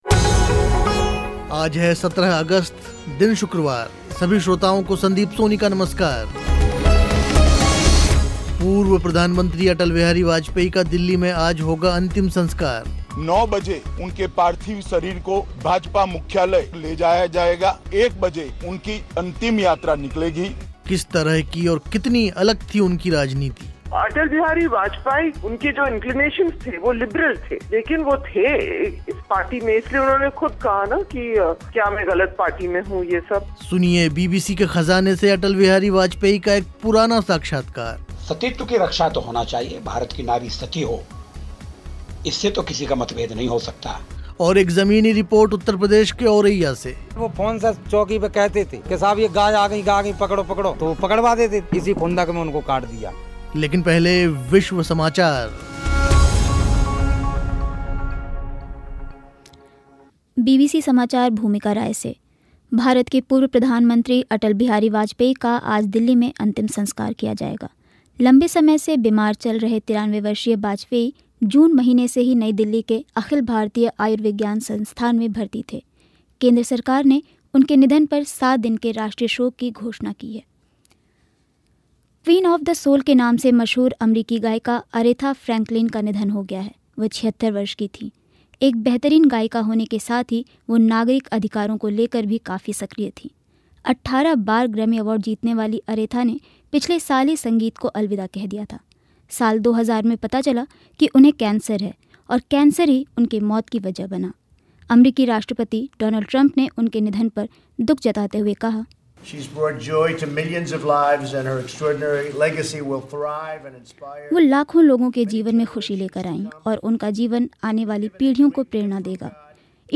सुनवाएंगे बीबीसी के ख़ज़ाने से अटल बिहारी वाजपेयी का एक पुराना साक्षात्कार. और एक ज़मीनी रिपोर्ट उत्तर प्रदेश के औरैया से.